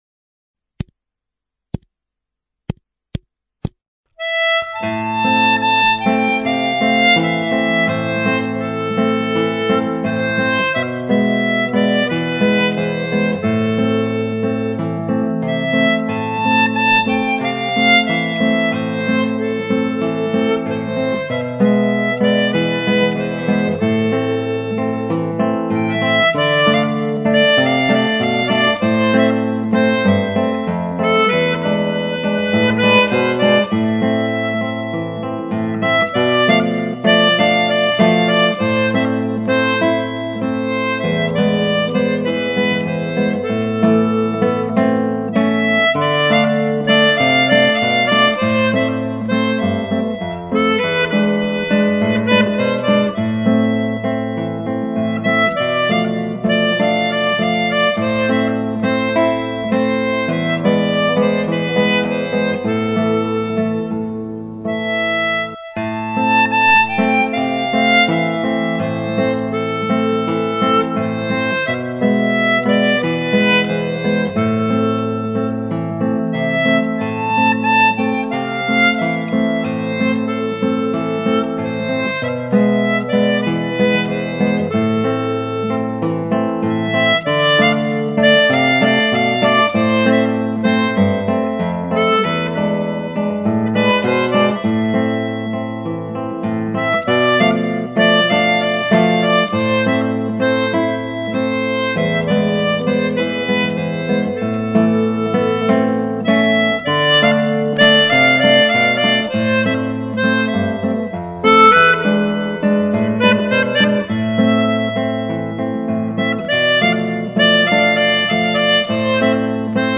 Sertaneja - Harmonica
Facile à jouer, en Do, c'est donc comme le titre une sertaneja, de la country brésilienne.
C'est très doux!